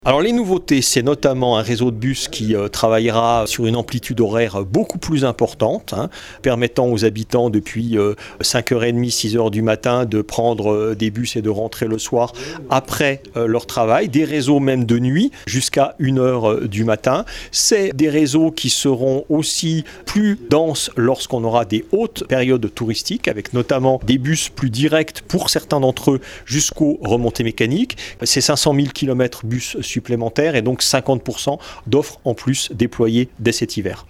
Eric Fournier est le maire de Chamonix et le Président de la communauté de communes de la vallée de Chamonix . Il nous détaille les nouveautés : Télécharger le podcast Partager :